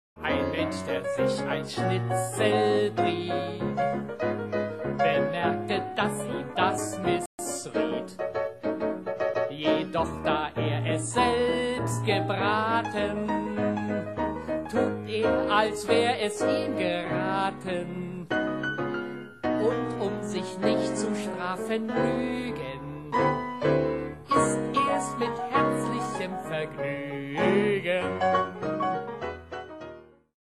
Heiteres Soloprogramm am Klavier